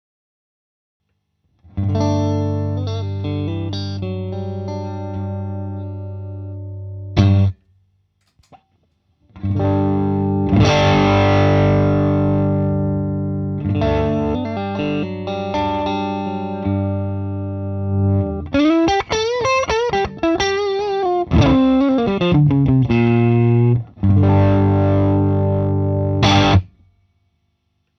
"Full gain” volume sulla chitarra da 6/7 a 10 strat 4th position